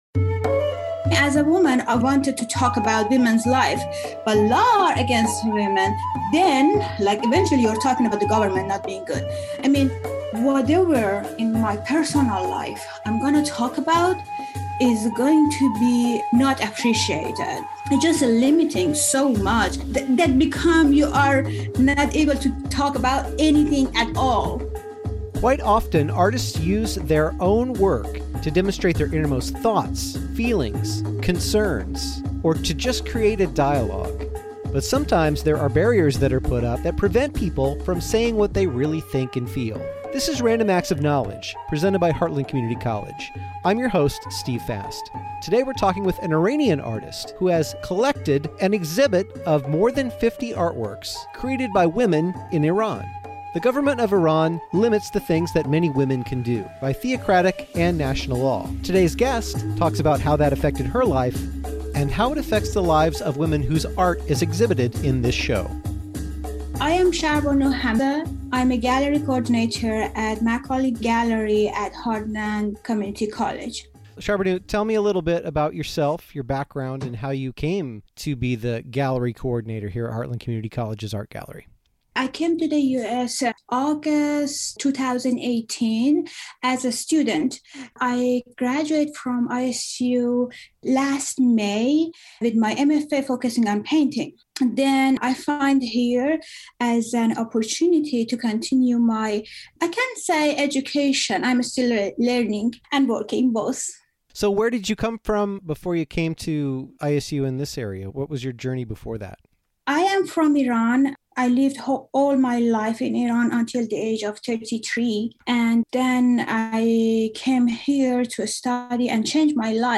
An Iranian artist speaks about her work, and the work of other women who face barriers not only of government censorship but even of self-censorship.